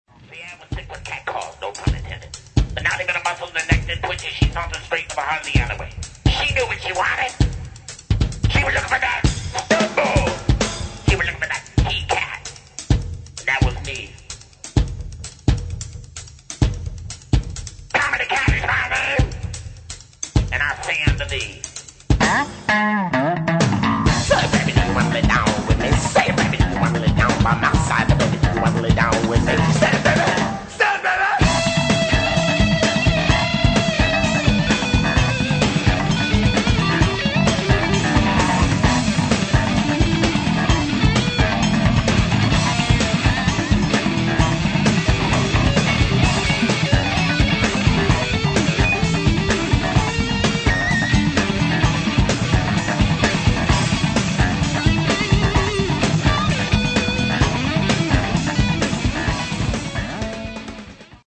Recorded at: Fantasy "D" Studio - Berkeley, CA